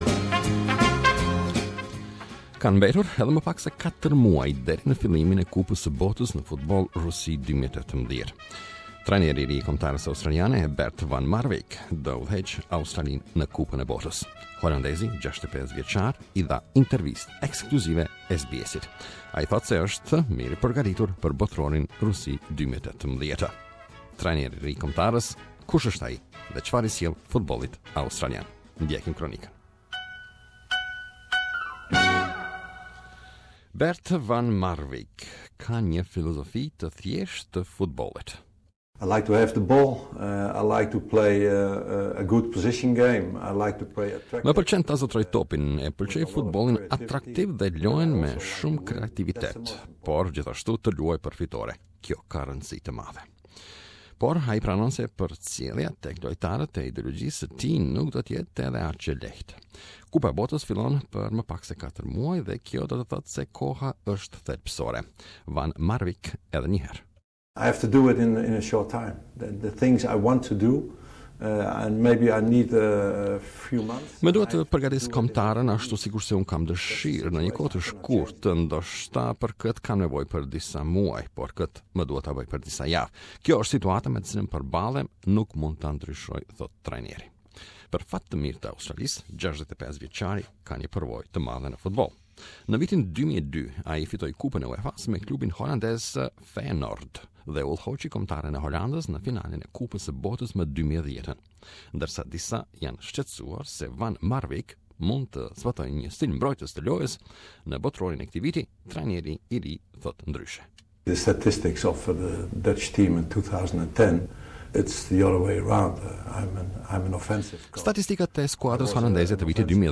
New Socceroos coach Bert van Marwijk [fon MAR-wayk] has been tasked with leading Australia to June's FIFA World Cup. Speaking exclusively to SBS, the 65-year-old Dutchman says he's ready to get started.